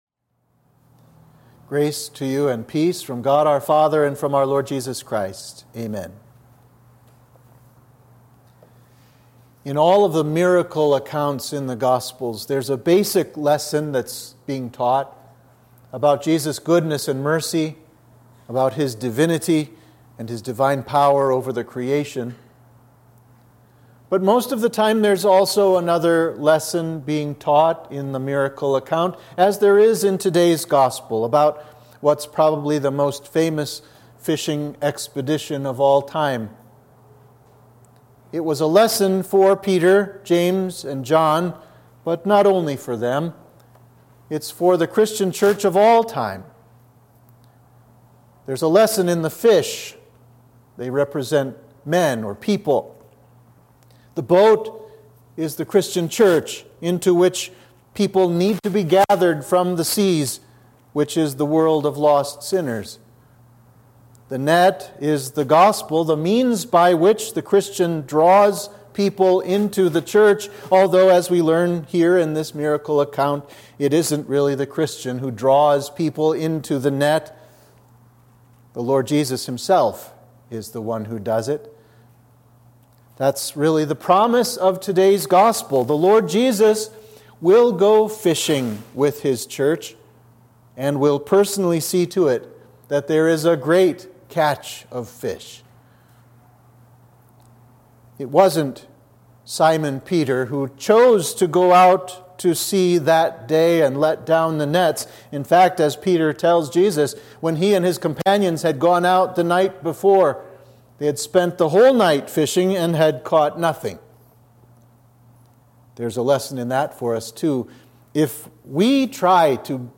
Sermon for Trinity 5